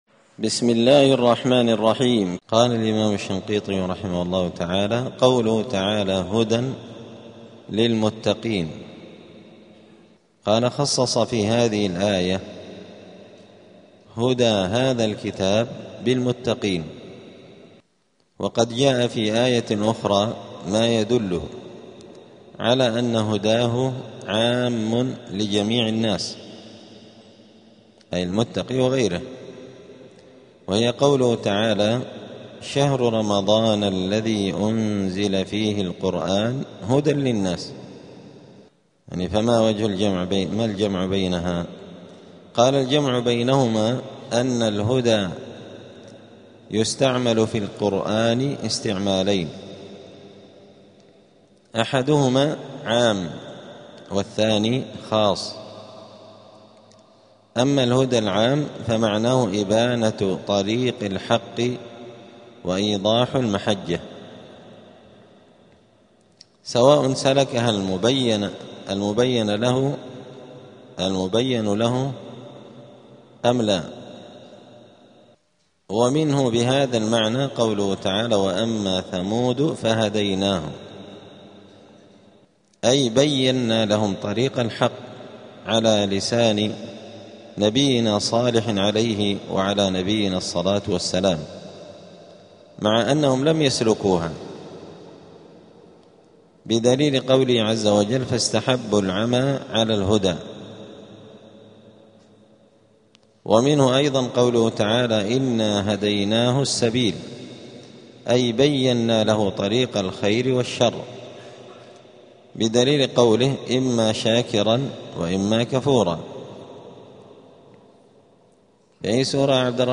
*الدرس الرابع (4) {سورة البقرة}.*
دار الحديث السلفية بمسجد الفرقان قشن المهرة اليمن